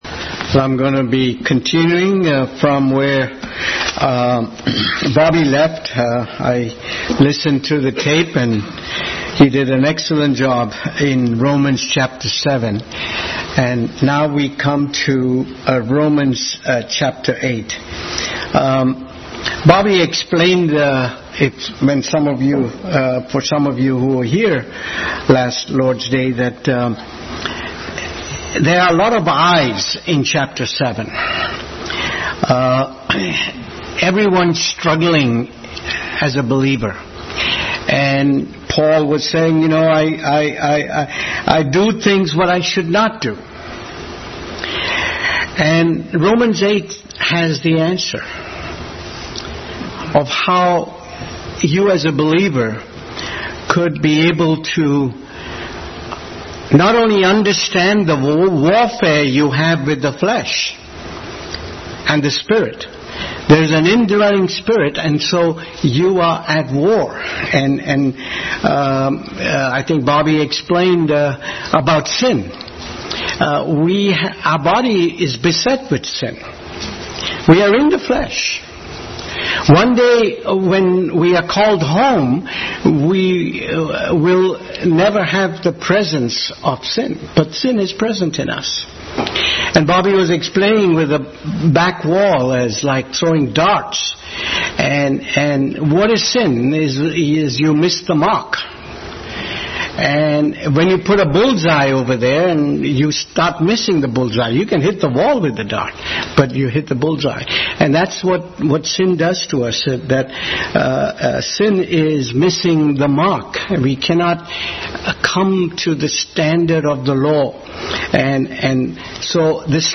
Romans 8:1-15 Service Type: Sunday School Bible Text